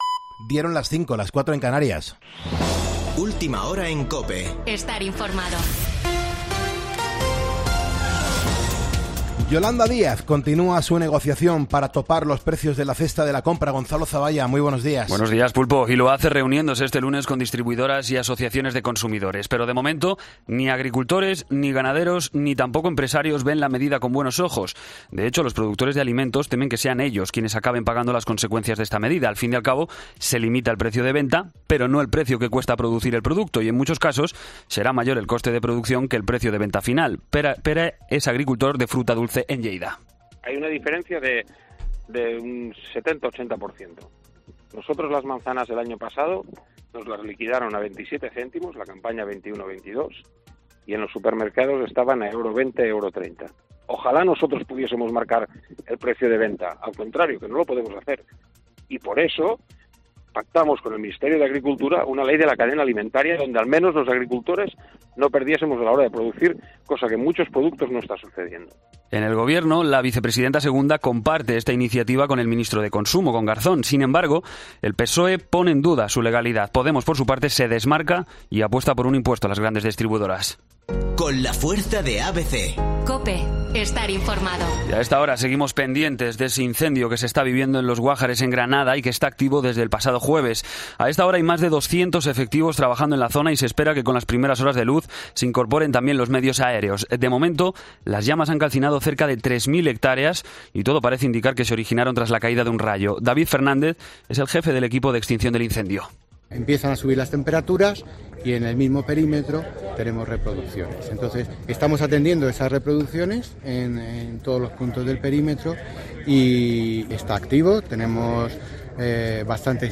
Boletín de noticias COPE del 12 de septiembre a las 05:00 horas